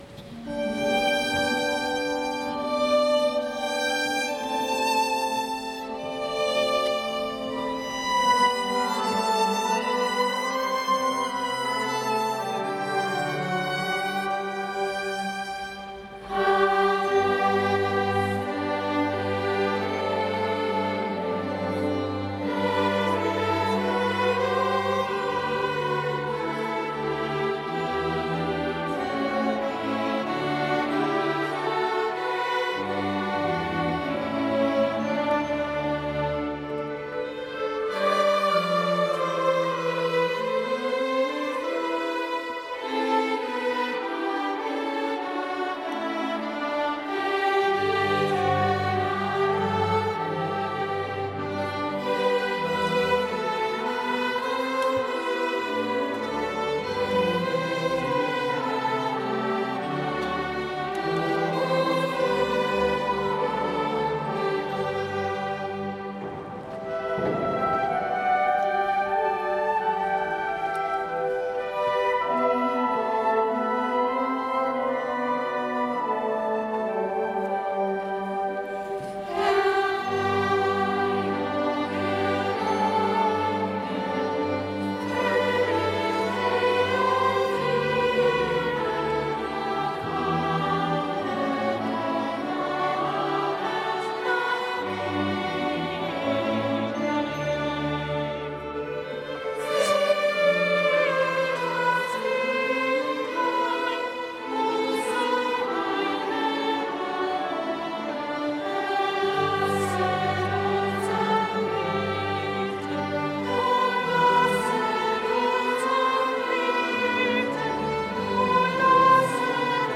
Unser diesjähriges Abschlusslied "Adeste fideles" mit allen Ensembles, Orgel und Publikum ist direkt aus dem Zuhörerraum aufgenommen.
Sinfonieorchester, Unterstufenchor, Vokalensemble, Kammerchor, Orgel und Publikum
Aufnahme aus dem Zuhörerraum